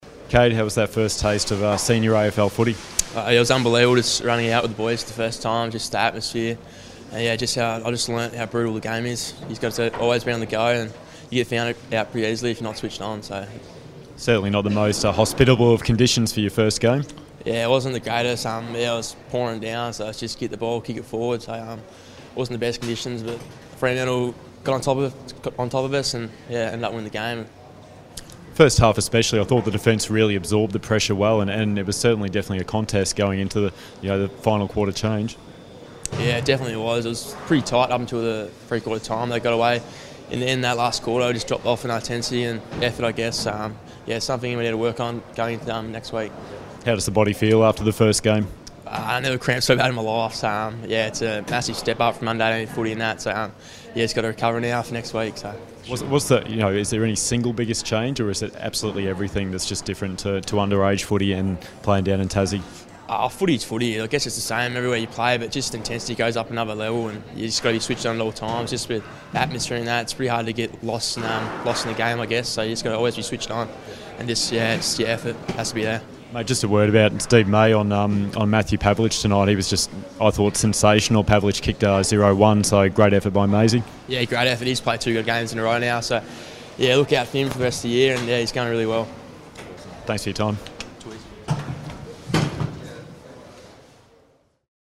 Round 2 post-match interview with Kade Kolodjashnij.